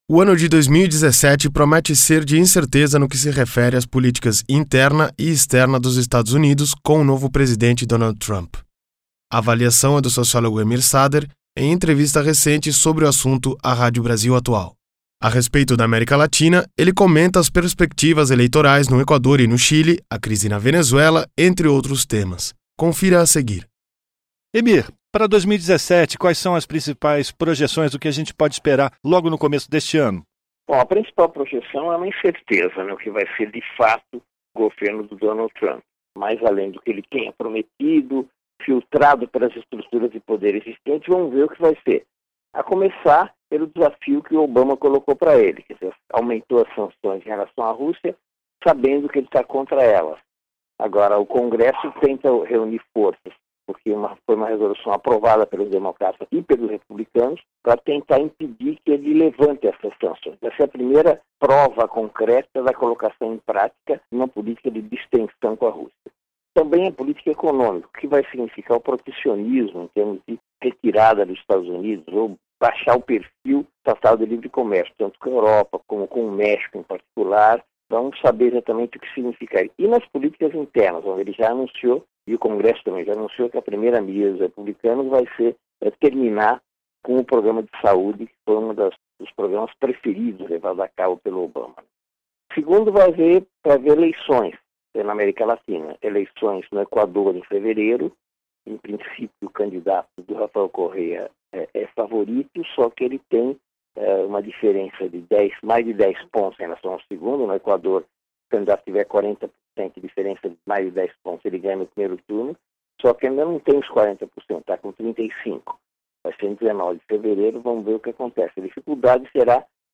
Entrevista: Emir Sader comenta cenário internacional para 2017